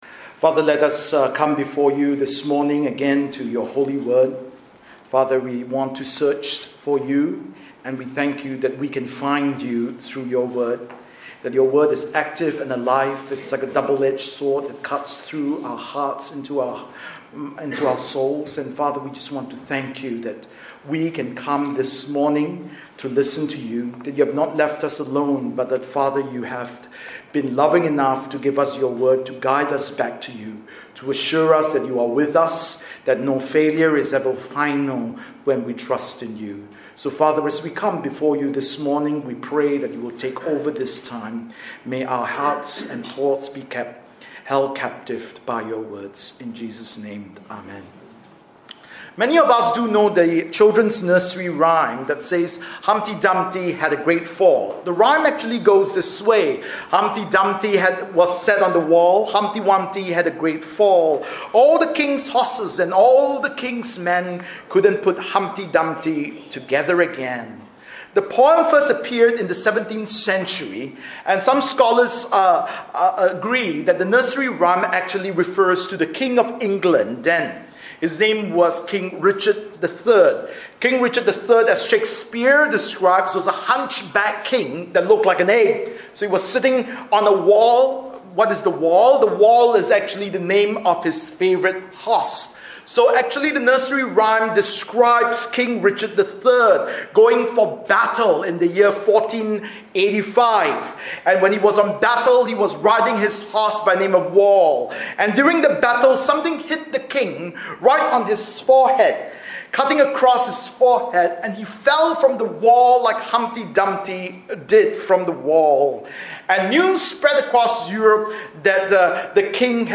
Humpty Dumpty Had a Great Fall | Evangelical Christian Church